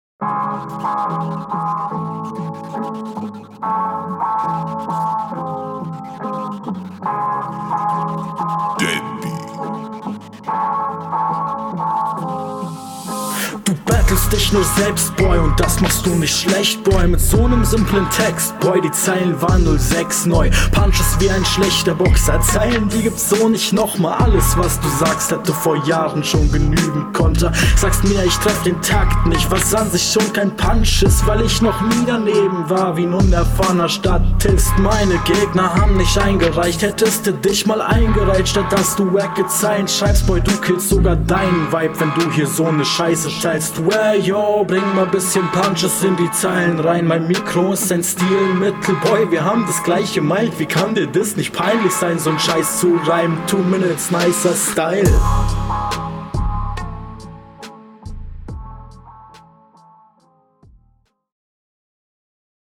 Uh hier wurde ein Effekt auf das Intro gelegt, ich bin gespannt.
Flow und Text sind wesentlich mehr on Point.